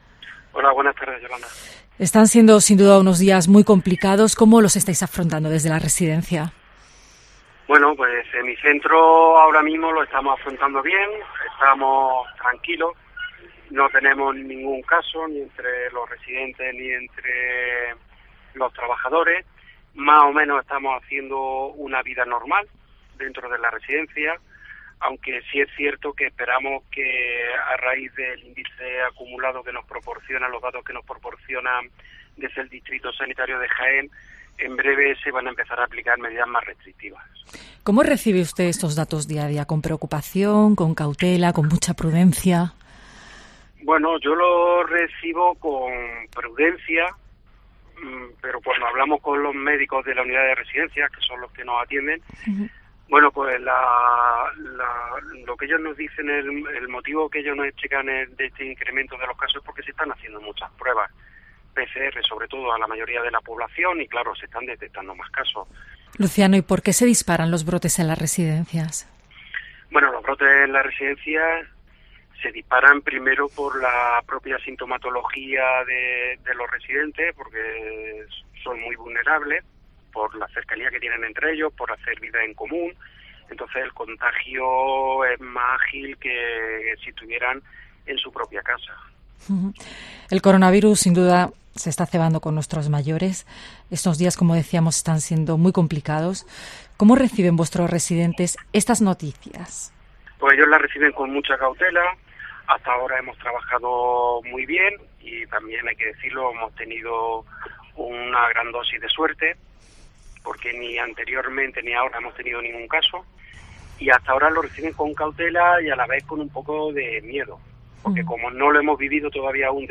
Durante la entrevista que puedes escuchar aquí, se muestra algo preocupado ante los brotes que van incrementando día a día en las residencias andaluzas.